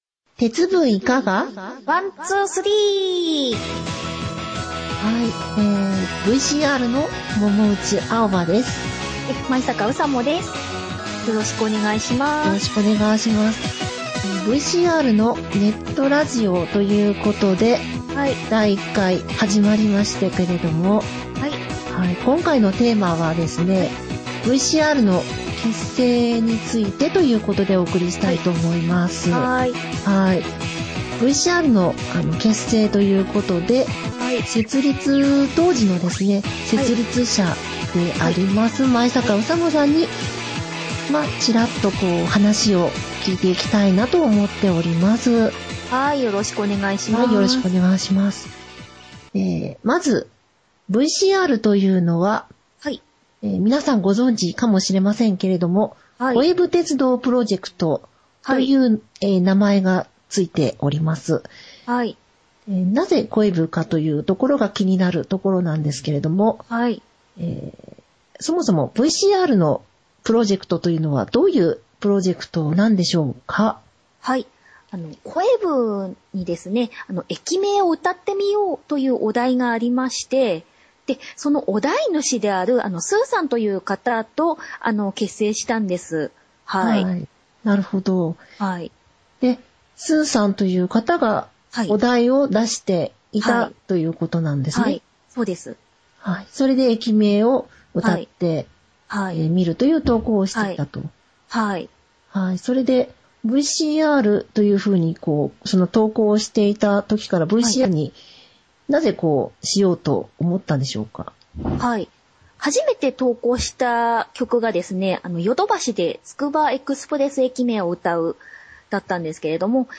ネットラジオ番組をポッドキャスト配信中